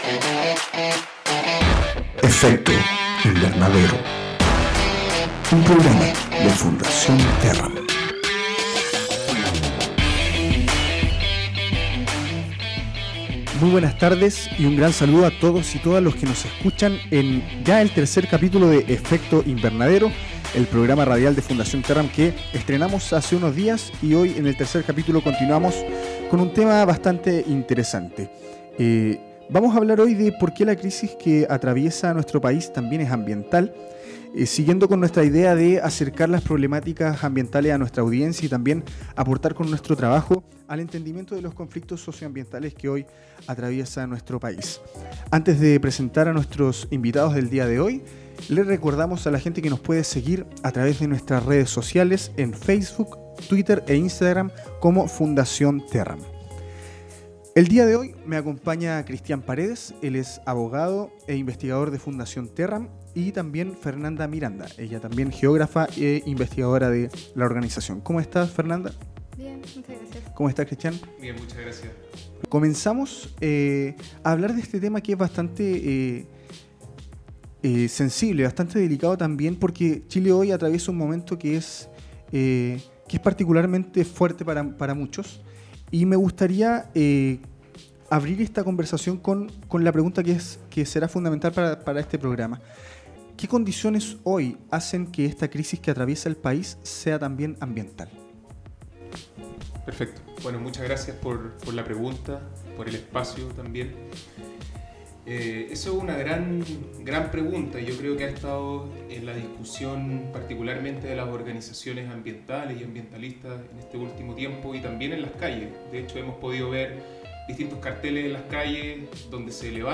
Además, hacia el final de la conversación, los panelistas dieron su punto de vista sobre la perspectiva ambiental que debería contener una nueva Constitución en Chile.